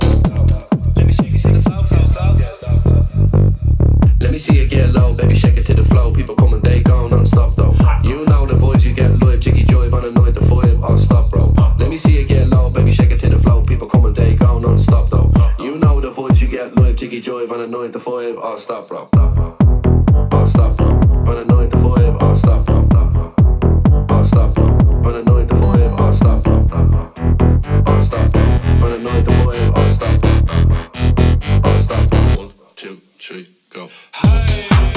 Жанр: Танцевальные / Хаус